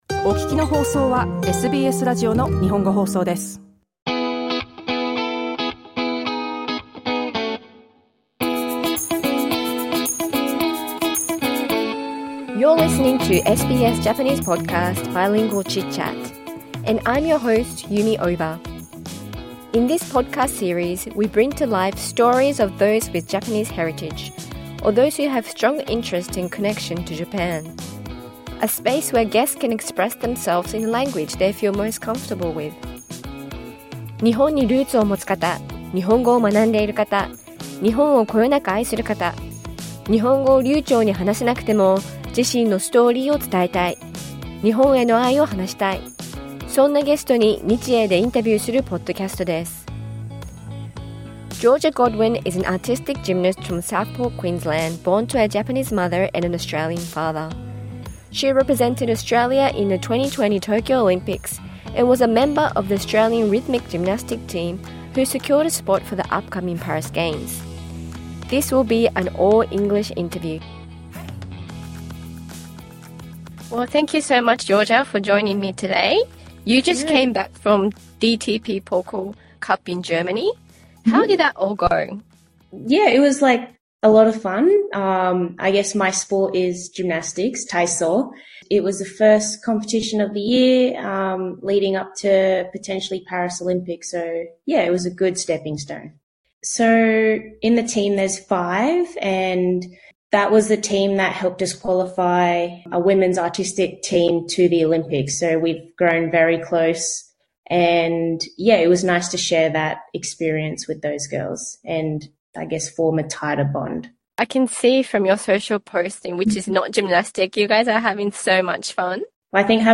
Credit: Georgia Godwin フルインタビューはポッドキャストをダウンロードしてください。